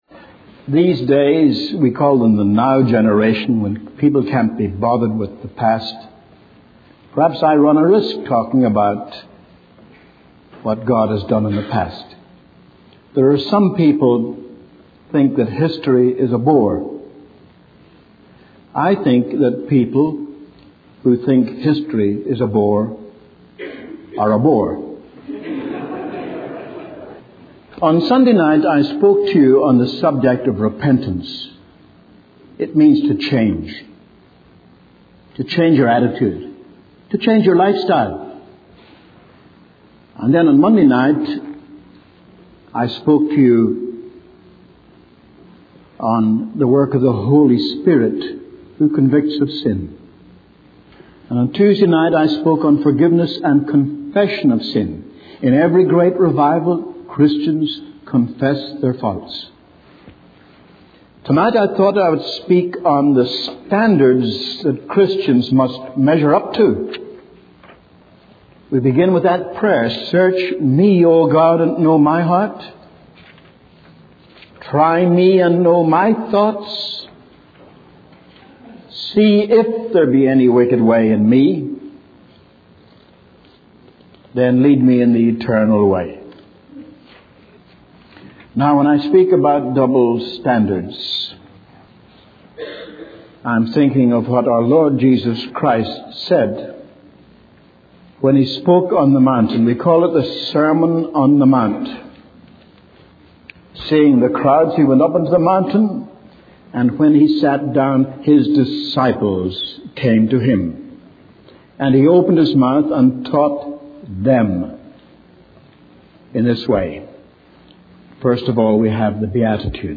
In this sermon, the preacher addresses the attitude of the 'now generation' that dismisses the importance of history.